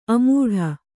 ♪ amūḍha